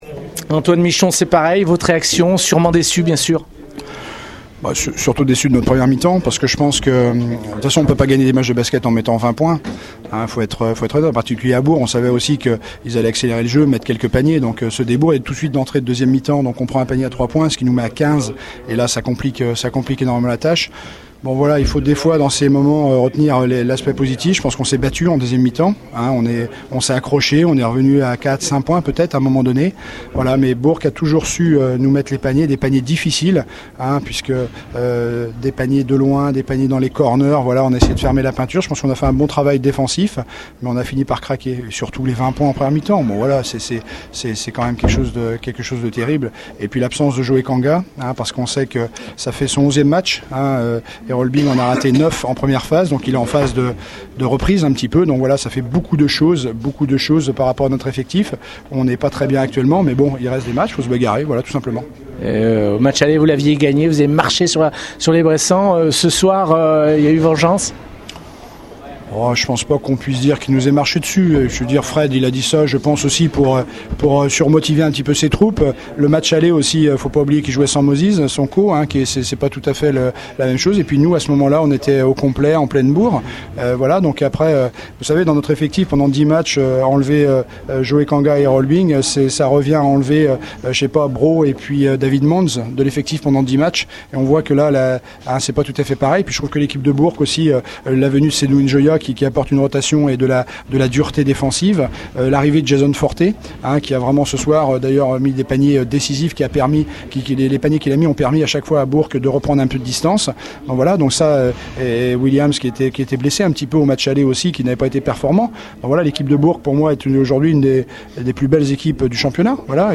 On écoute les réactions d’après-match